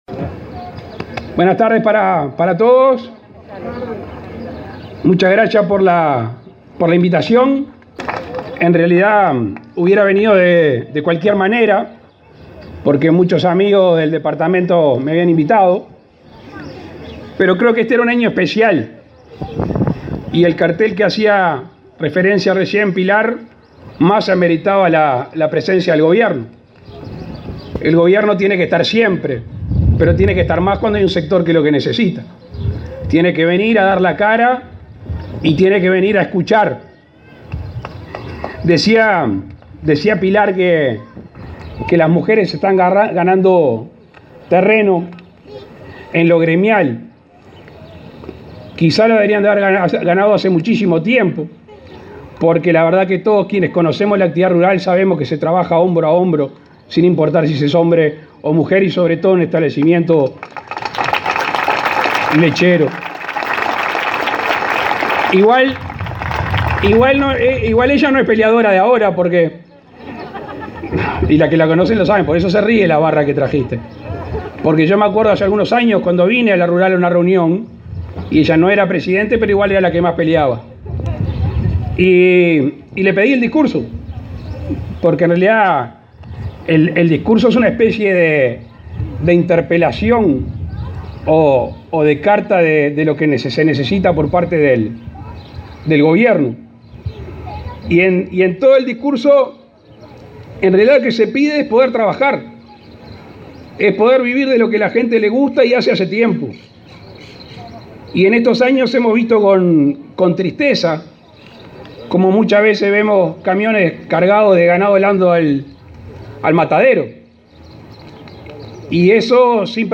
El presidente de la República, Luis Lacalle Pou, encabezó este domingo 11 la ceremonia de clausura de la 76.ª edición de la Exposición Internacional